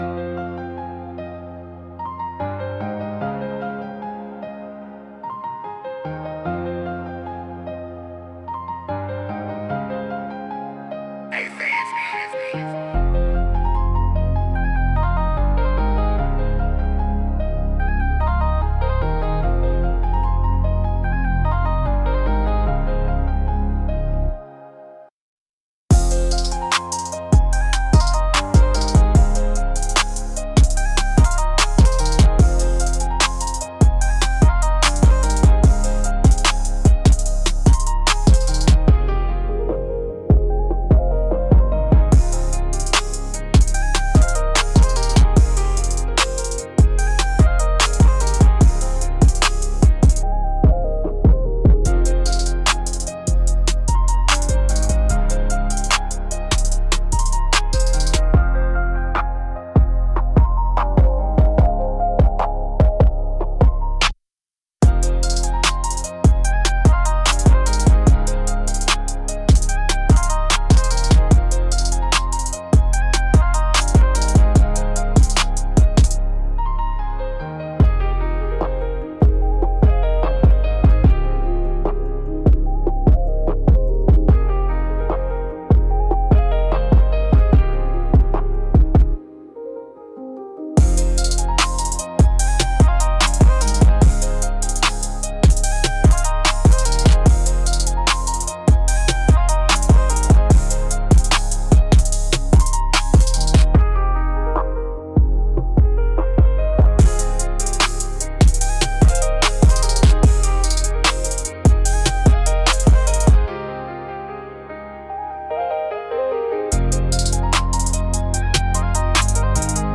128 BPM
Trap